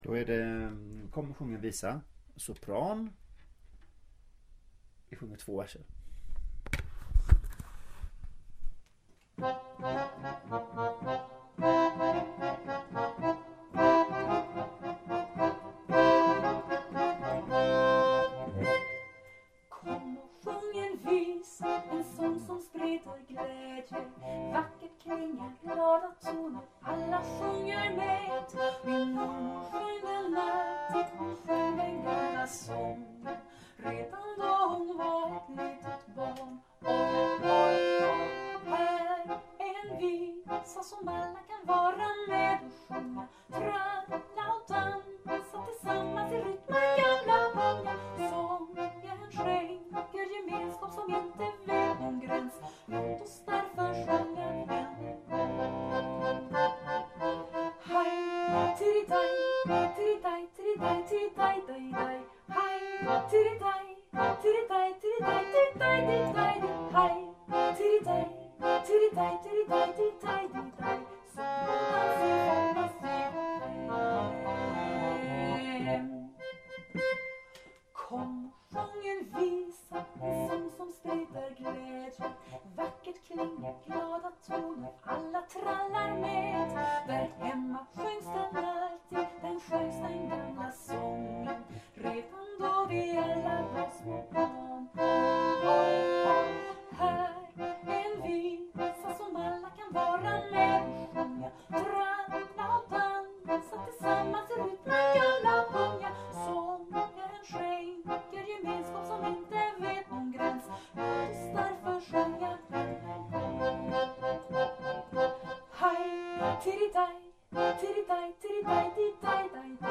Kom och låt oss sjunga sopran
Komochlatosssjunga_sopran.mp3